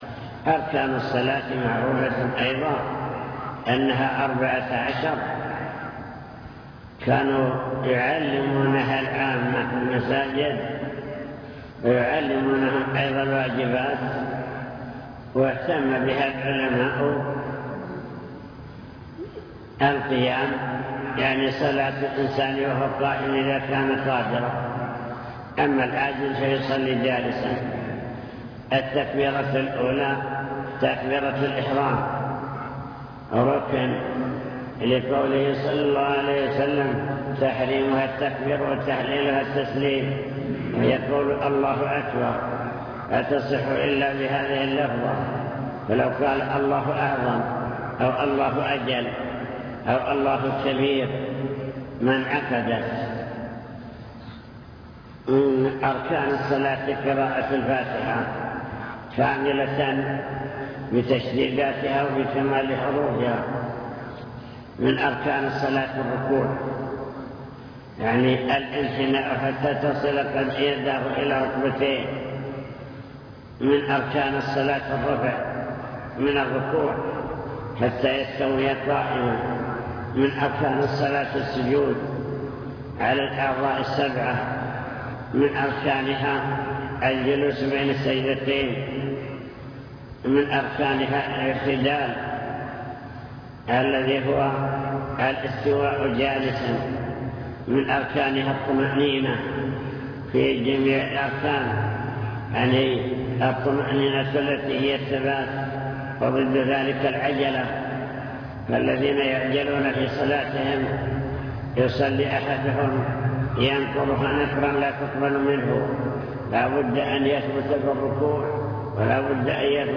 المكتبة الصوتية  تسجيلات - محاضرات ودروس  الإسلام والإيمان والإحسان